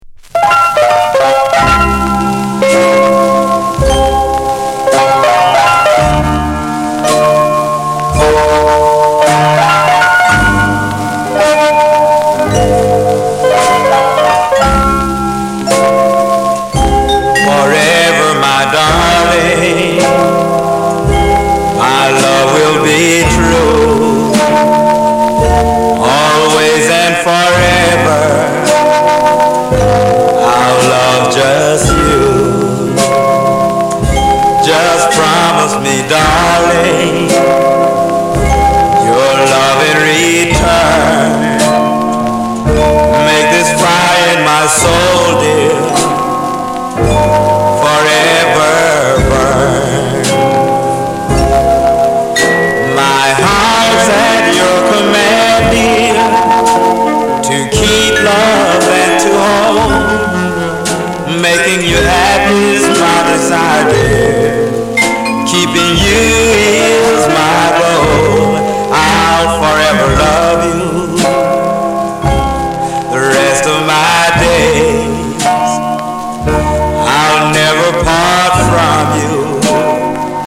Genre: Blues